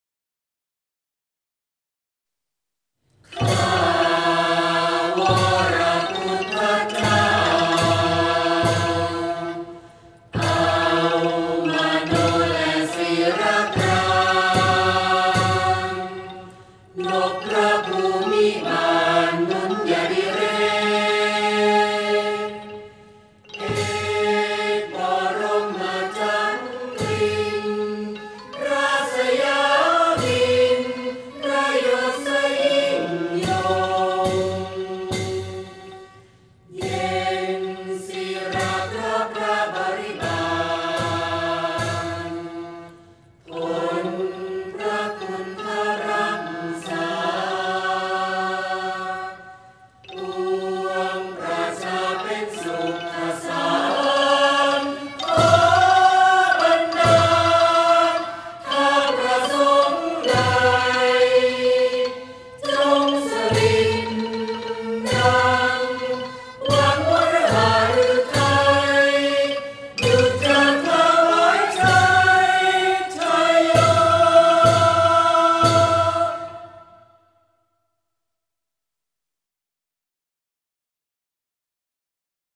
วงปี่พาทย์-ไม้แข็ง